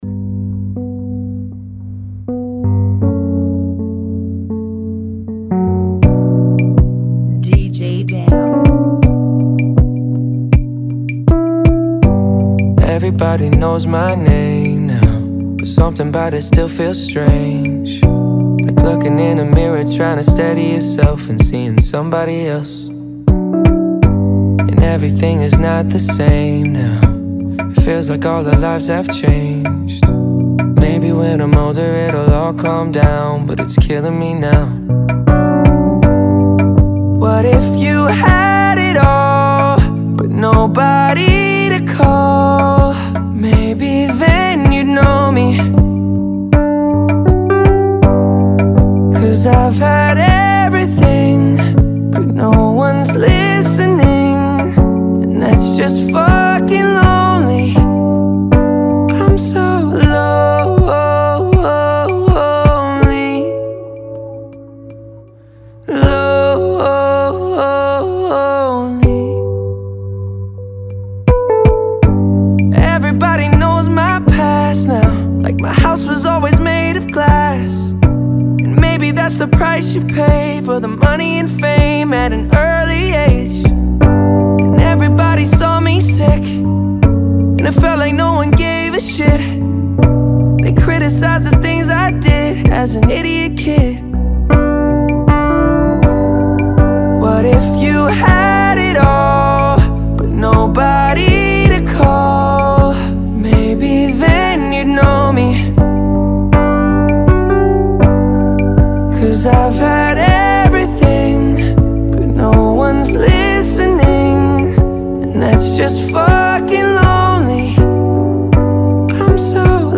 Genre: Kizomba Remix